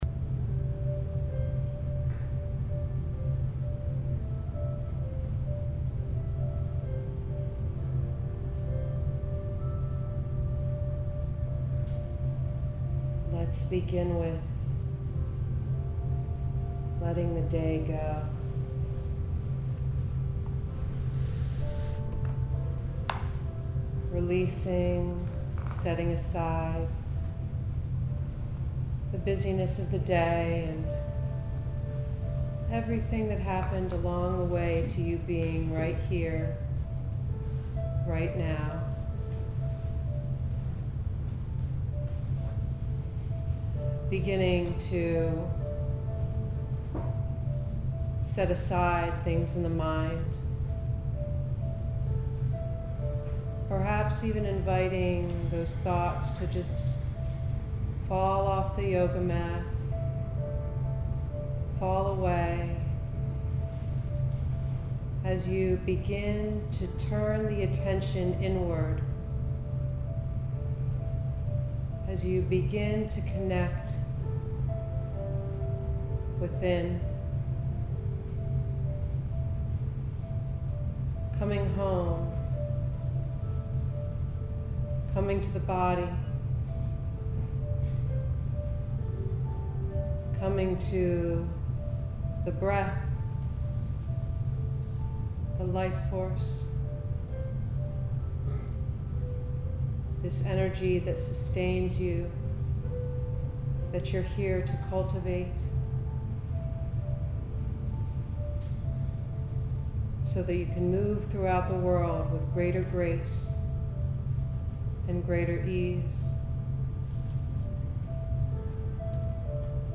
60 MINUTE GENTLE STRETCH YOGA CLASSES – AUDIO